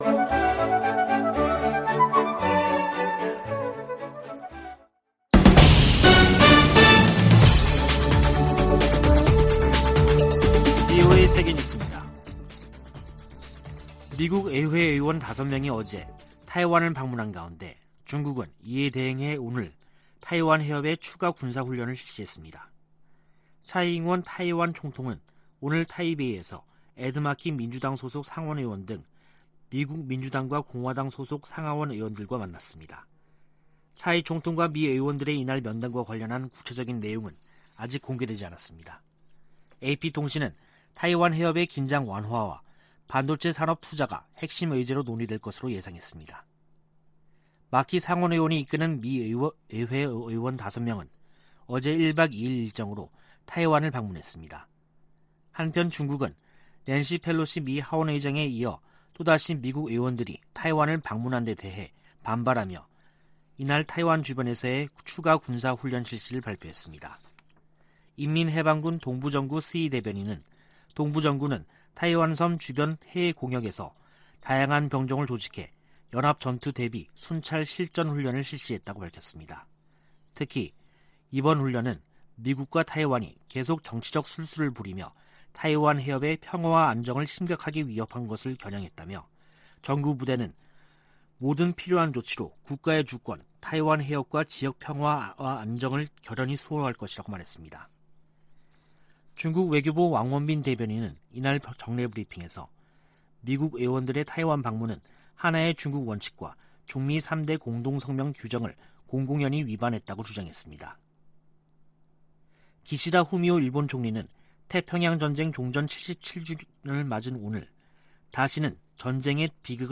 VOA 한국어 간판 뉴스 프로그램 '뉴스 투데이', 2022년 8월 15일 2부 방송입니다. 윤석열 한국 대통령이 광복절을 맞아, 북한이 실질적 비핵화로 전환하면 경제를 획기적으로 개선시켜주겠다고 제안했습니다. 중국의 ‘3불 1한’ 요구에 대해 해리 해리스 전 주한 미국대사는 중국이 주권국가에 명령할 권리가 없다고 지적했습니다. 중국이 낸시 펠로시 미국 하원의장의 타이완 방문을 구실로 도발적인 과잉 반응을 지속하고 있다고 백악관 고위관리가 규탄했습니다.